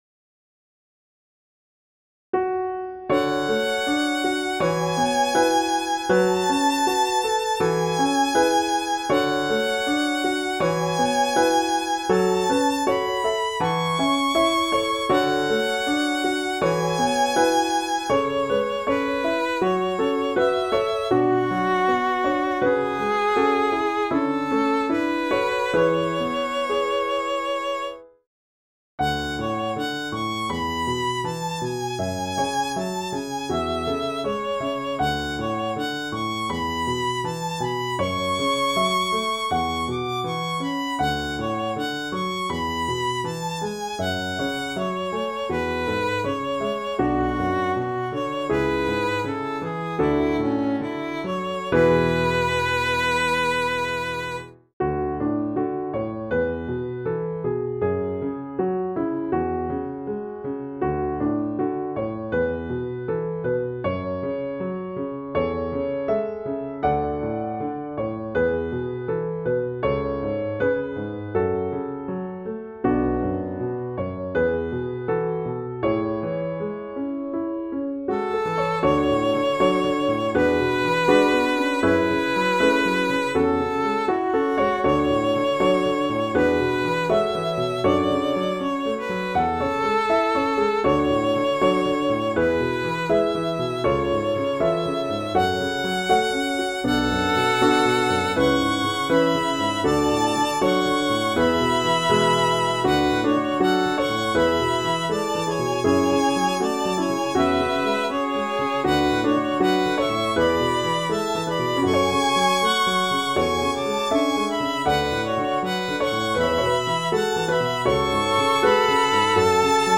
初めてのピアノ＋ヴァイオリンのデュオ曲となりました。
自分の中ではかなり上手く2つの楽器が噛み合ったと思ったりしているのですがどうでしょうか？
また音源を変えたので、ピアノソロでは音がかなりこもっているように感じられます。
しかしヴァイオリンの音は、二分音符にかかるビブラートの感じとかが凄く気に入っているのです。
最初はヴァイオリンを単音でメロディのようにしていたのですが、途中から重音にしてしまいました。
ピアノに関しては、コーダの和音で「今まで出したくて、でも出せなかった空気」を演出できたので、大変気に入っています。
全体を通して、私としては今までで一番まとまりのある切なげな曲に仕上がったと思っているのですがどうでしょうか…？